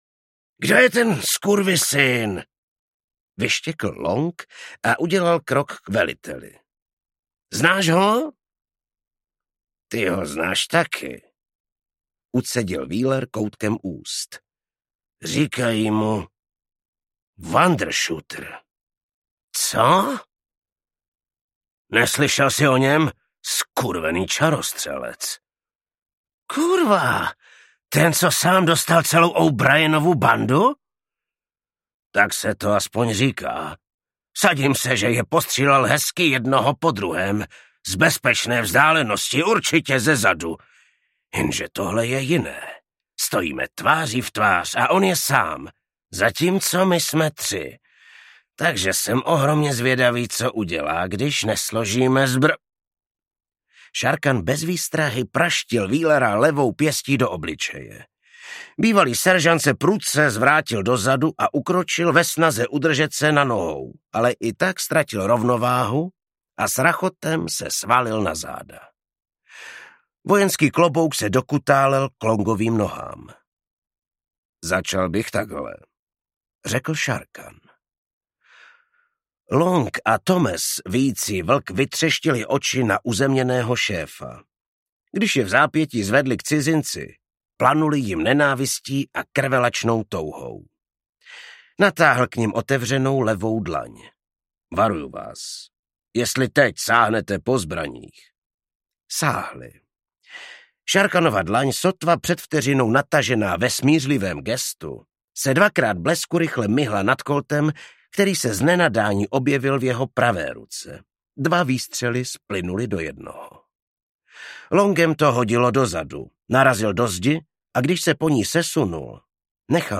Zakletý klášter audiokniha
Ukázka z knihy
Vyrobilo studio Soundguru.